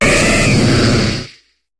Cri d'Aligatueur dans Pokémon HOME.